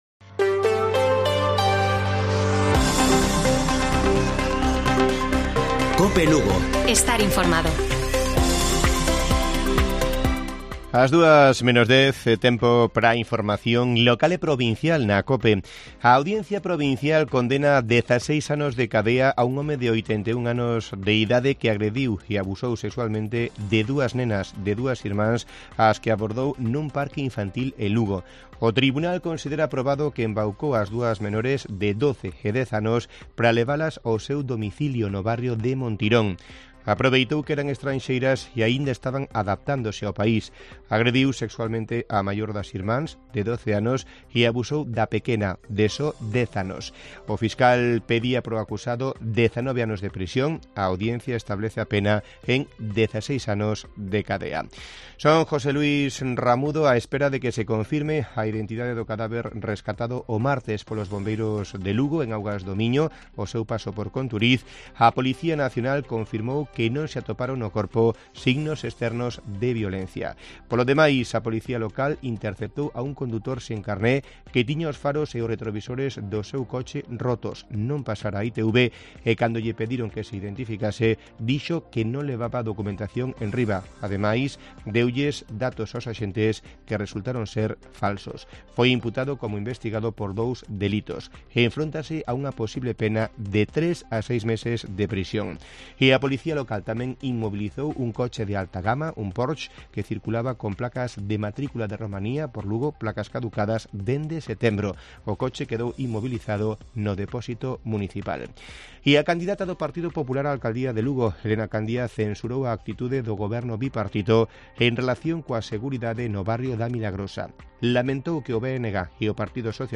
Informativo Mediodía de Cope Lugo. 23 de marzo. 13:50 horas